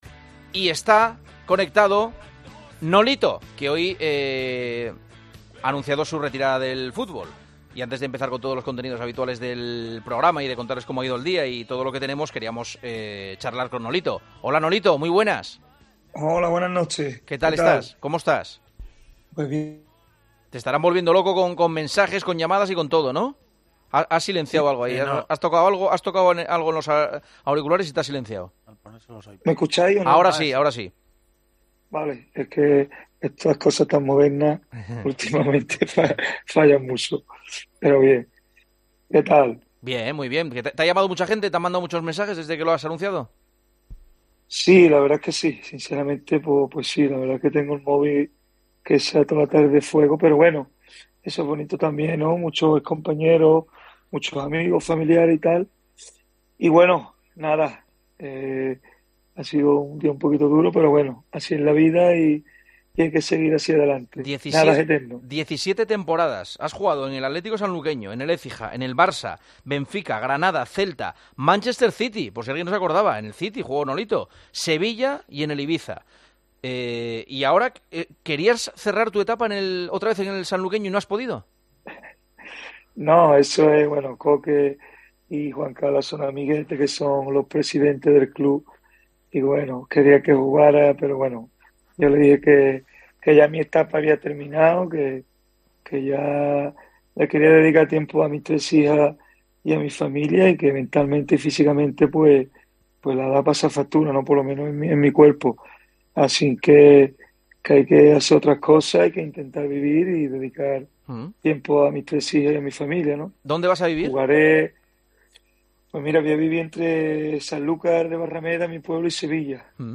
El atacante de 36 años ha anunciado que cuelga las botas tras 17 años en activo y destacó varios momentos de su carrera en El Partidazo de COPE junto a Juanma Castaño. El futbolista destacó a Luis Enrique como su entrenador favorito.